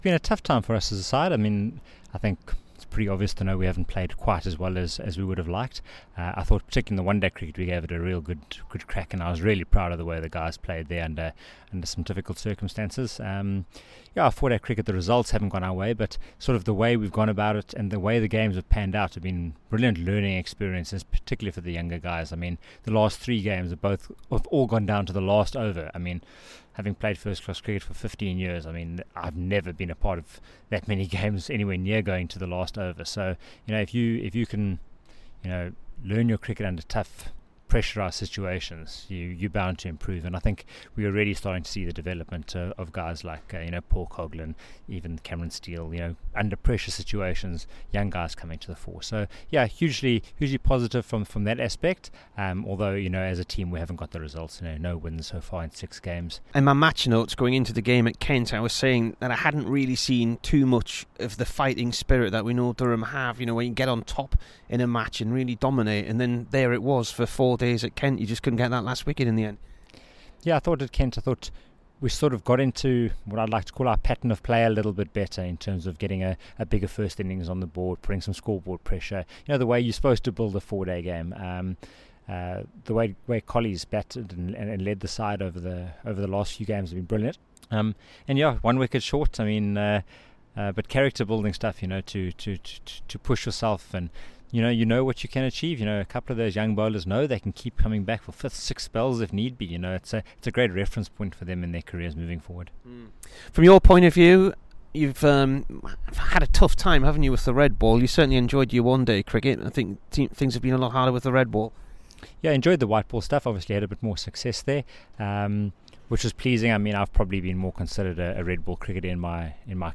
STEVE COOK INT
THE SOUTH AFRICAN BATSMAN TALKS TO BBC NEWCASTLE AHEAD OF HIS FINAL GAME, WHICH IS AGAINST GLAMORGAN.